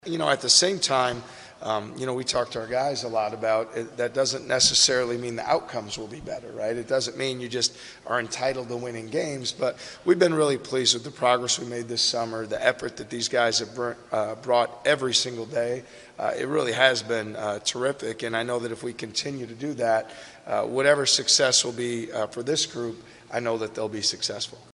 Iowa State basketball coach T.J. Otzelberger says the style of play has been set and it is now about building on it.